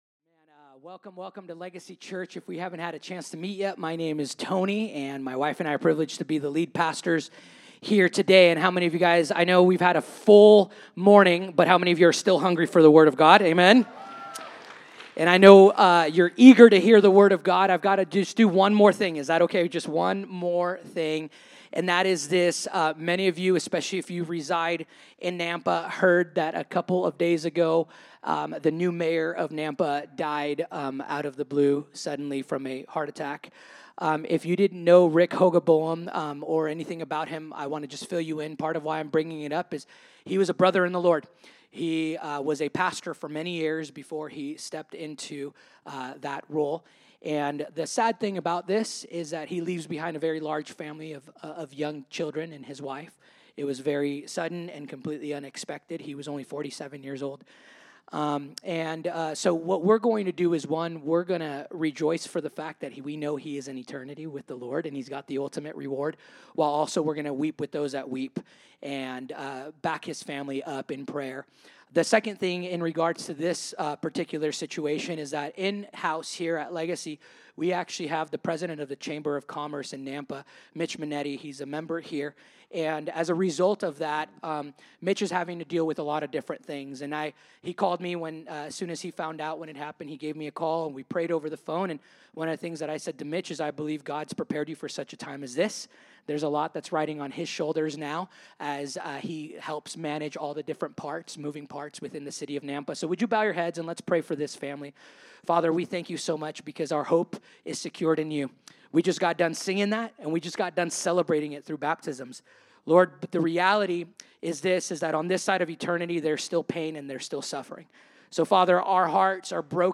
Legacy Church Sunday Messages